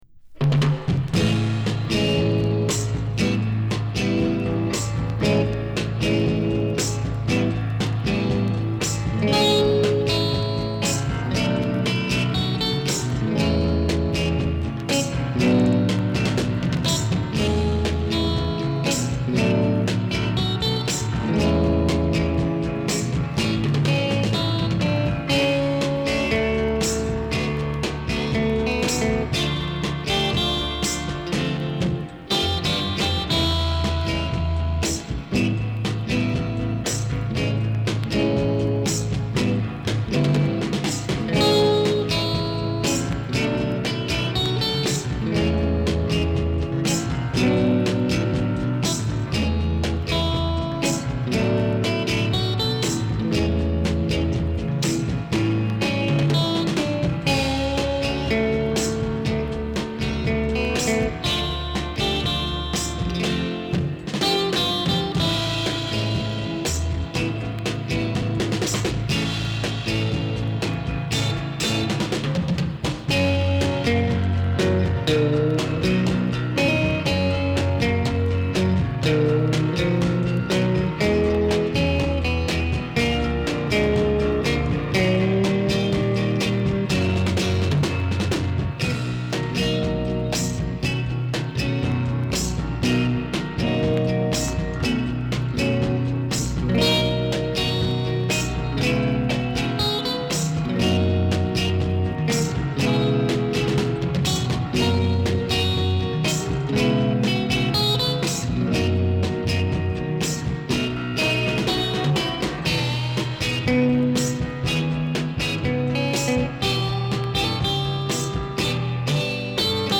• INSTRUMENTAL / SURF